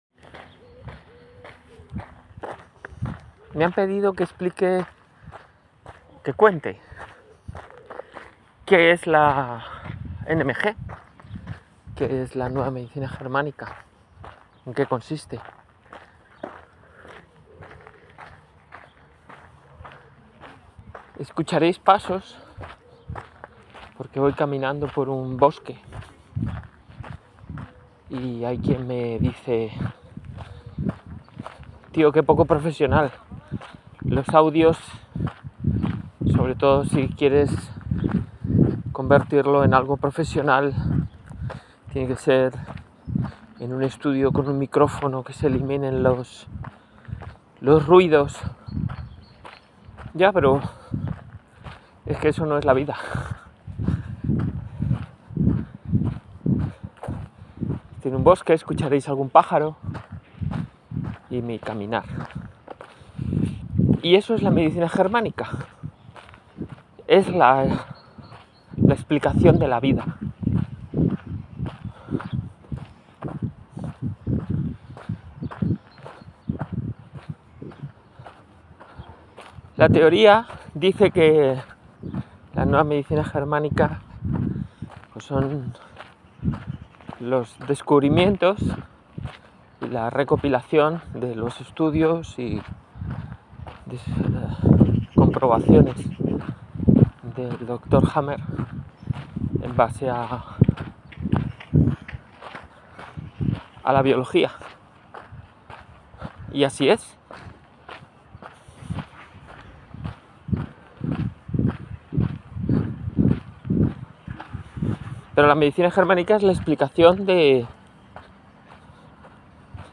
En este audio, te invito a descubrir la esencia de la Nueva Medicina Germánica (NMG) mientras caminamos juntos por un bosque, escuchando los sonidos de la naturaleza.